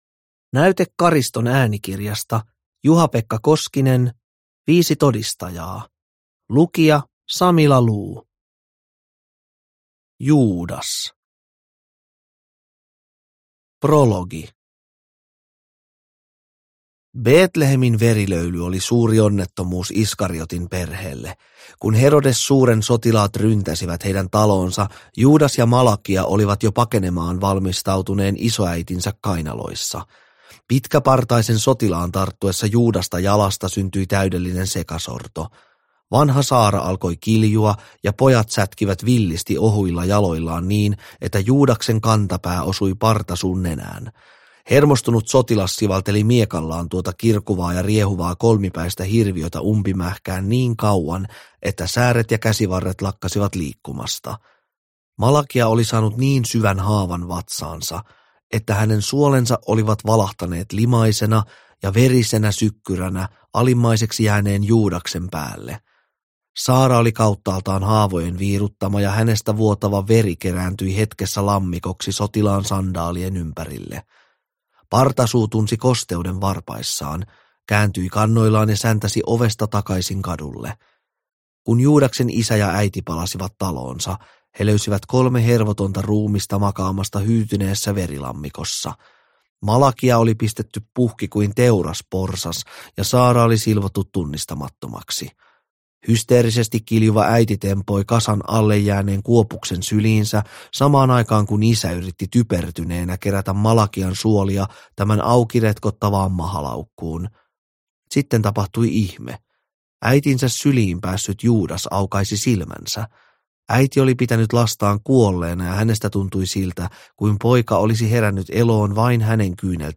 Viisi todistajaa – Ljudbok – Laddas ner